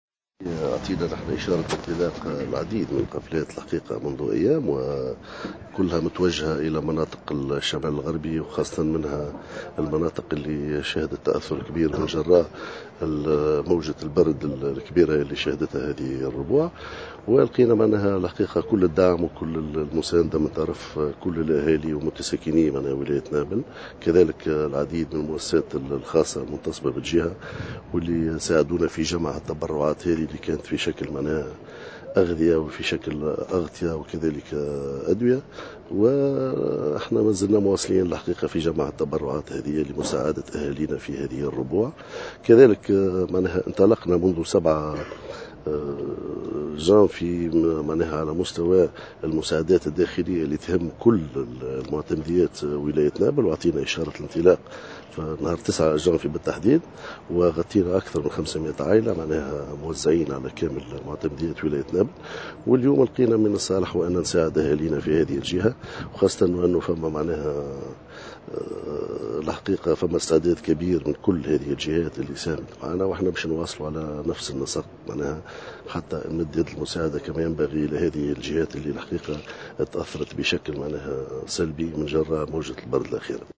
أكد والي نابل منور الورتاني في تصريح لمراسلة الجوهرة "اف ام" اليوم الإثنين 23 جانفي 2017 انطلاق قوافل مساعدات بإتجاه ولايات الشمال الغربي بعد موجة البرد الأخيرة التي شهدتها.